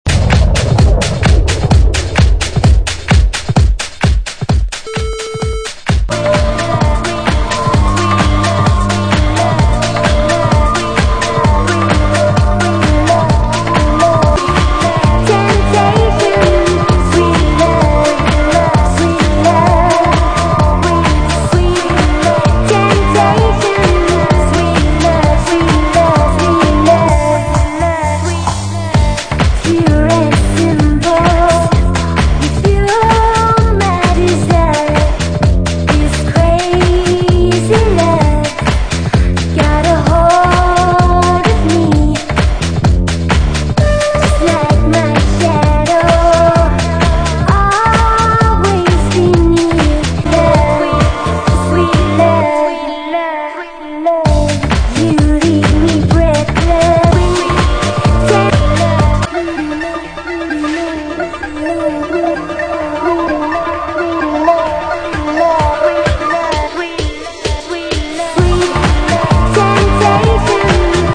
Вот кусочек трека..обалденный трек..нечего сказать,вокал,летний,мелодичный..супер,опазнайте пожалуйста.